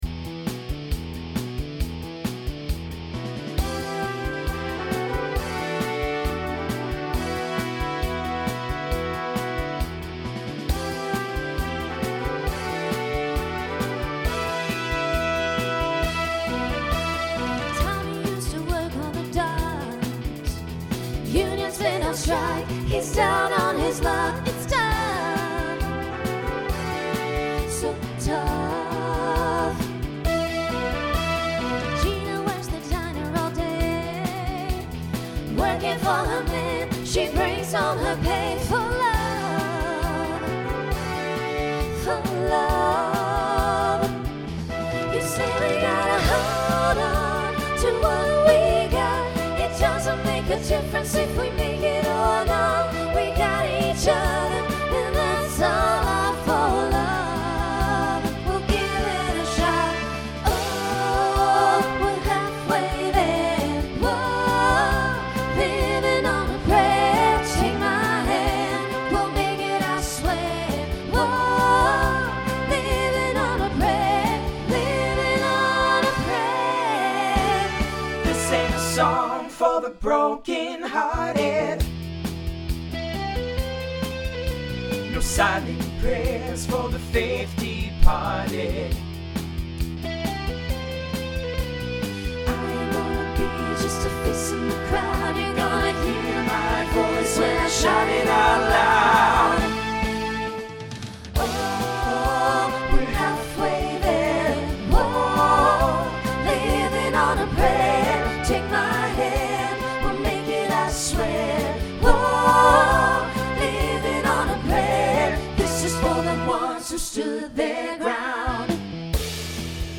Starts SSA, mostly SATB.
Voicing Mixed Instrumental combo Genre Rock